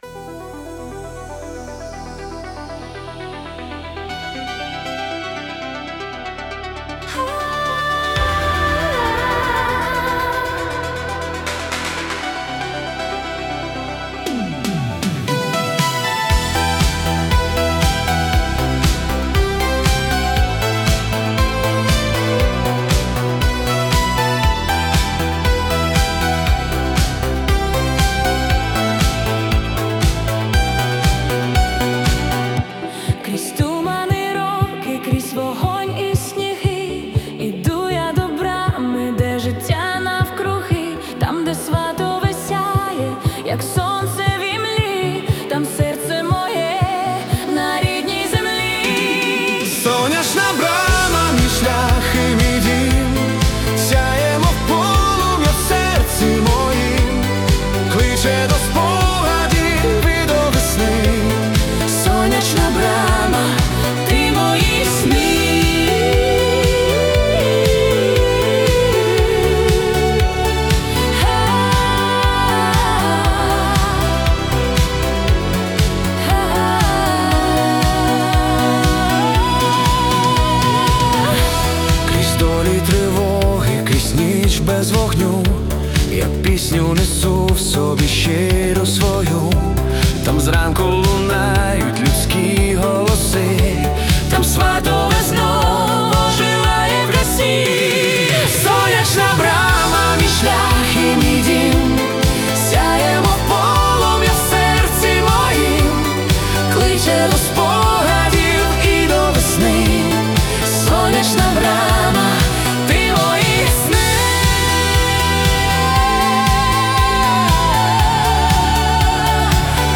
🎵 Жанр: Cinematic Pop / Synthwave